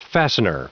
Prononciation du mot fastener en anglais (fichier audio)
Prononciation du mot : fastener